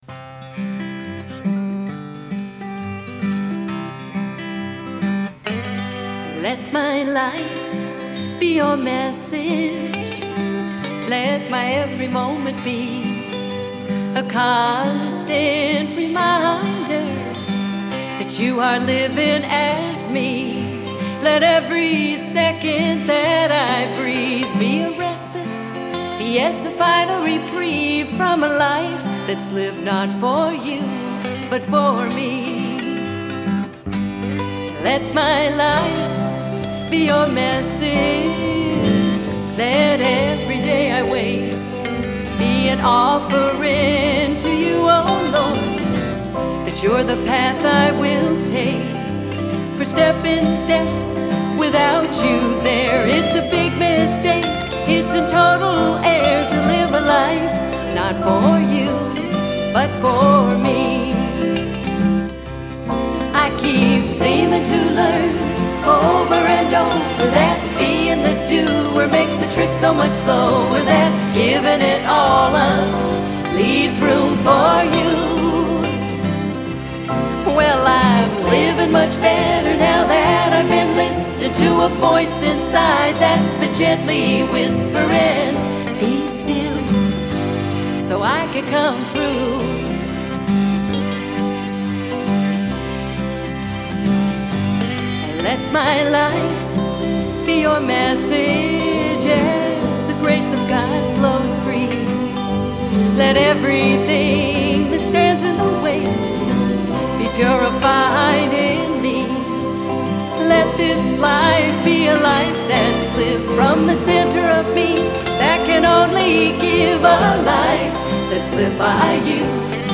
1. Devotional Songs
Major (Shankarabharanam / Bilawal)
8 Beat / Keherwa / Adi
Medium Fast
5 Pancham / G
2 Pancham / D
Lowest Note: p / G (lower octave)
Highest Note: P / G